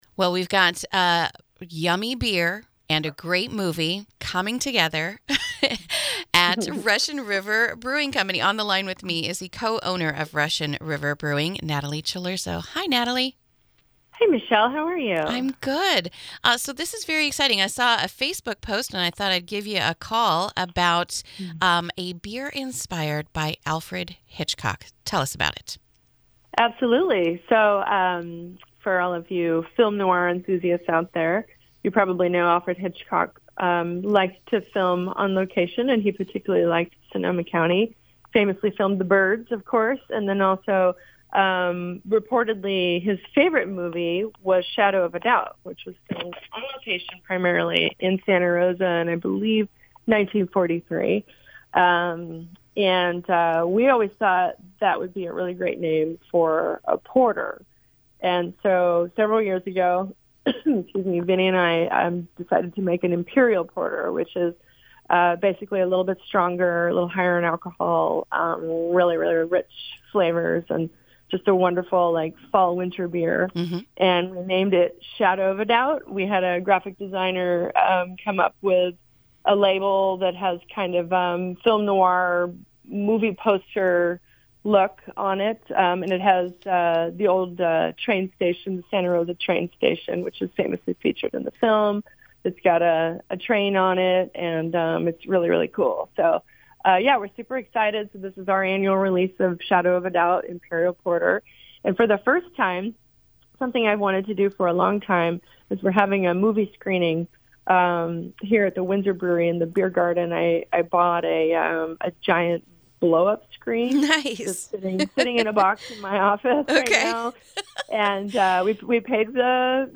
INTERVIEW: Russian River Brewing Hosts Hitchcock Screening